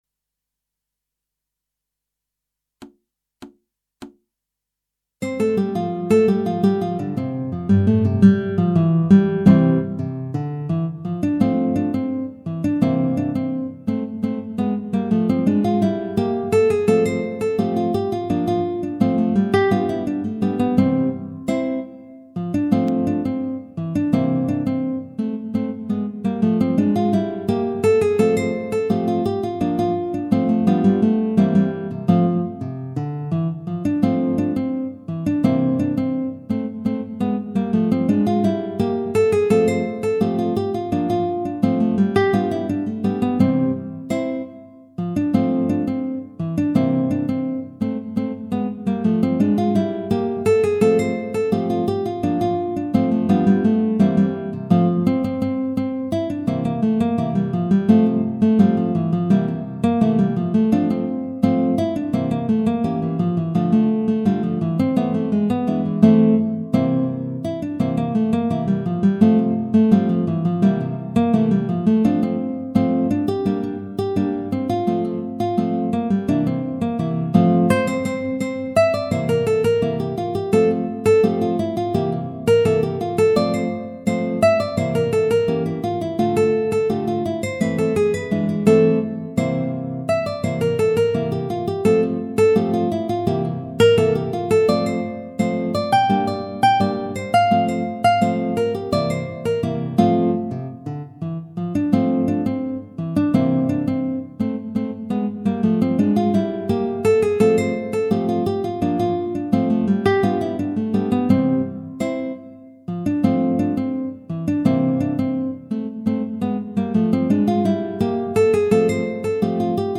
Guitar trio
minus Guitar 3